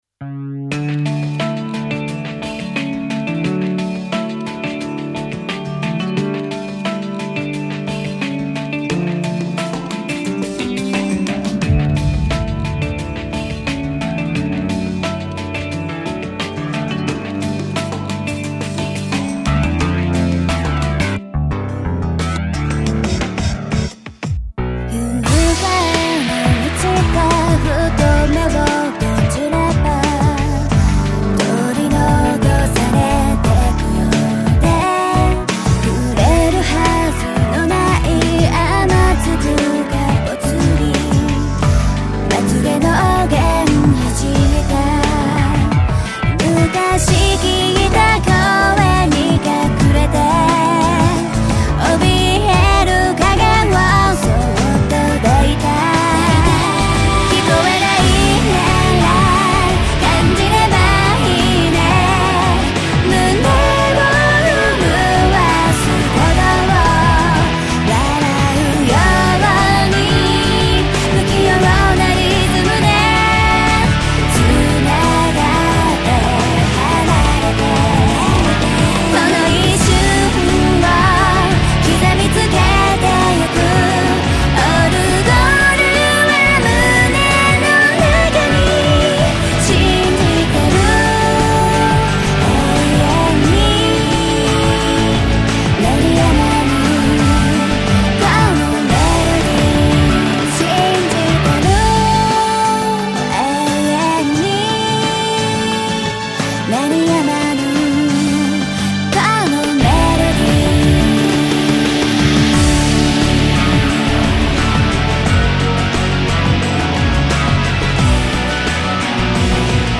主題歌